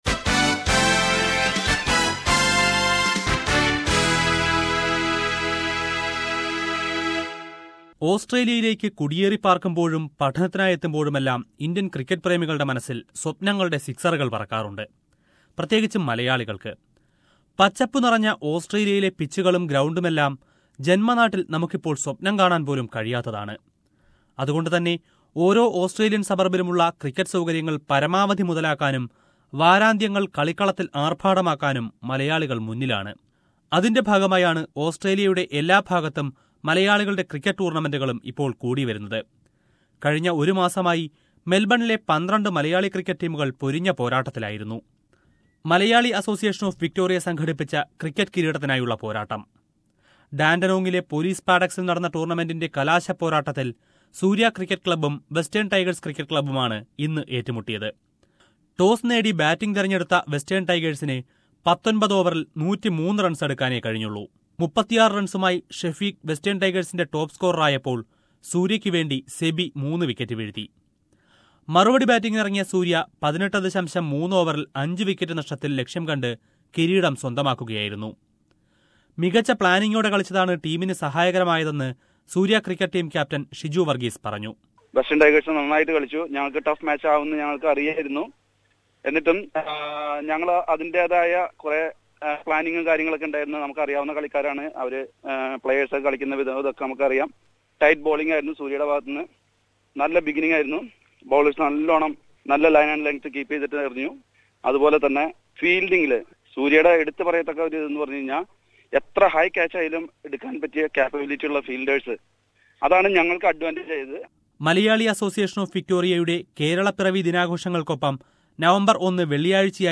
The Malayalee Association of Victoria (MAV) recently held a cricket tournament with the participation of 12 teams from around Victoria that lasted for a month. Listen to a coverage on the final match between the Soorya Cricket Club and The Western Tigers Cricket Club held at the Dandenong Police Parade Ground….